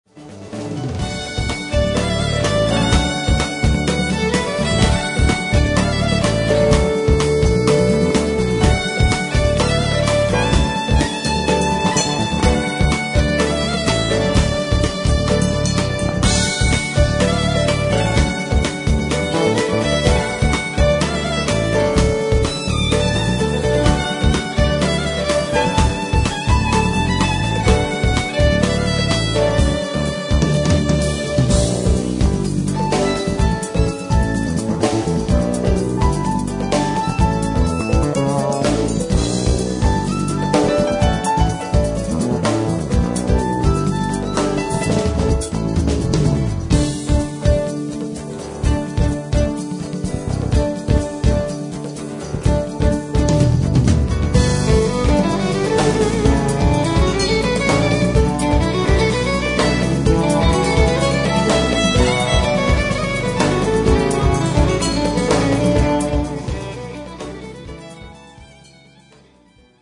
ドリーミーでハッピーな最高傑作
女性コーラスをフィーチャーし
流麗で美しいメロディが印象的です。
bass, synth programs
guitars
percussion
trumpet, flugelhorn, violin
piano, keyboards
drums
guitar
soprano saxophone
tabla
vocal